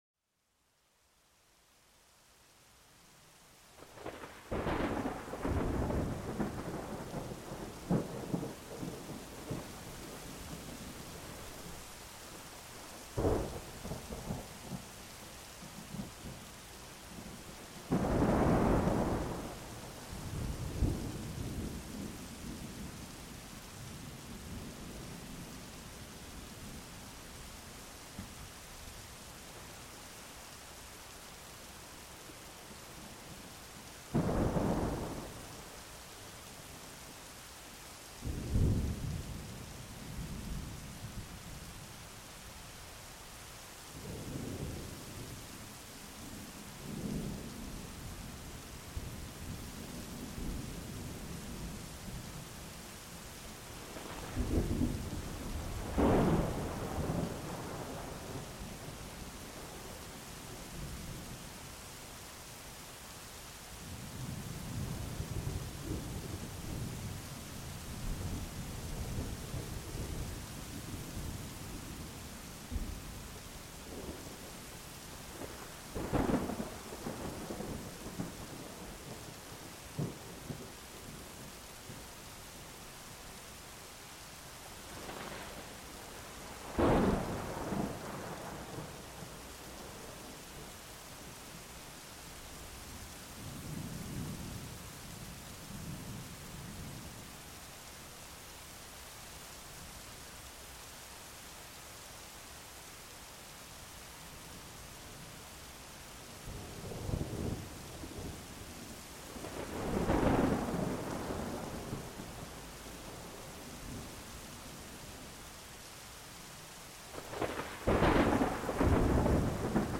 Cet épisode vous plonge dans le son puissant et apaisant de l'orage, créant une atmosphère propice à la relaxation. Laissez-vous bercer par le tonnerre lointain et la pluie battante, qui lavent le stress du quotidien.